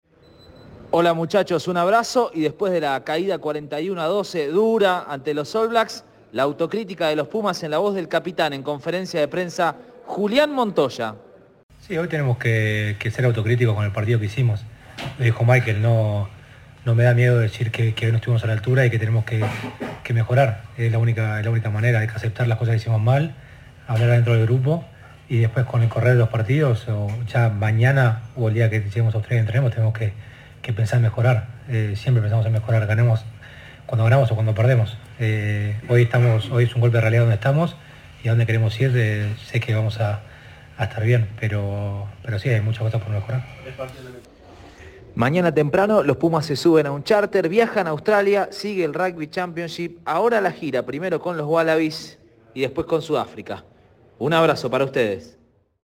Tras la derrota, el capitán del seleccionado argentino, Julián Montoya habló en conferencia de prensa.